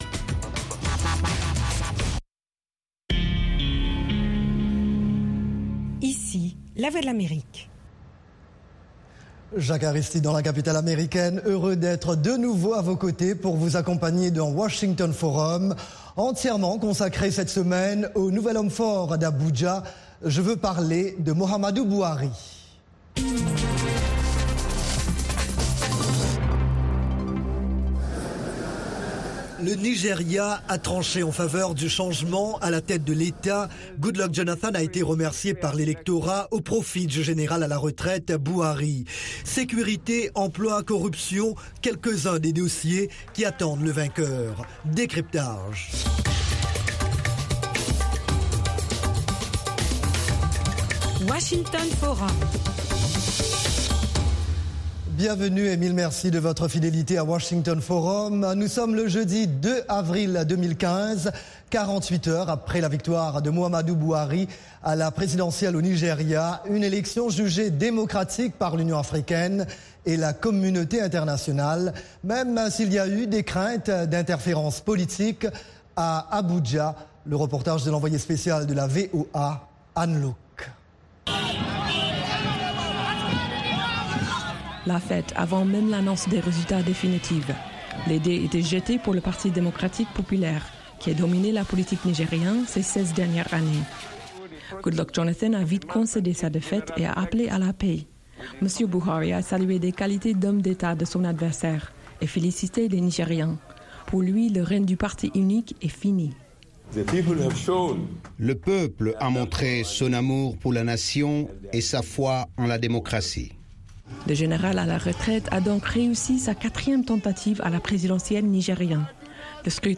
Washington Forum : 30 minutes d'actualité africaine, américaine et internationale. Economie, politique, santé, religion, sports, science, multimédias: nos experts répondent à vos questions en direct, via des Live Remote, Skype, et par téléphone de Dakar à Johannesburg, en passant par le Caire, New York, Paris et Londres. Cette émission est diffusée en direct par satellite à l’intention des stations de télévision et radio partenaires de la VOA en Afrique francophone.